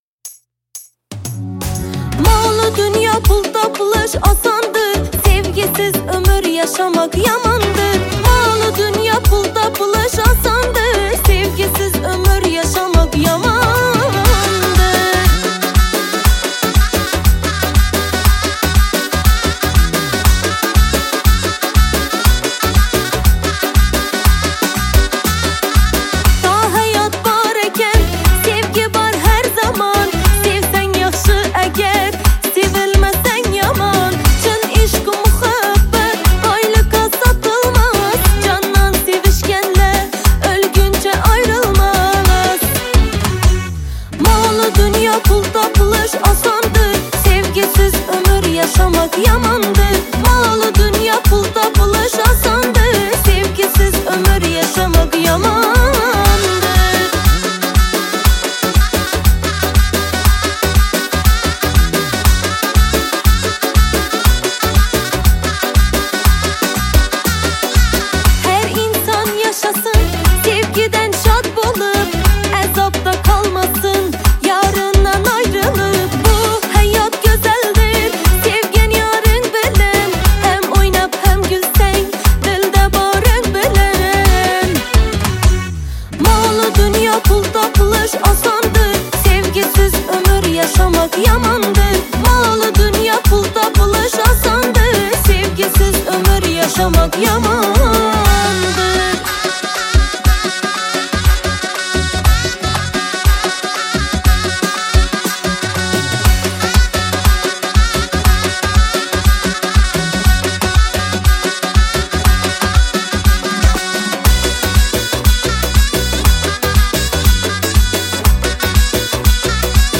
• Жанр: Узбекские песни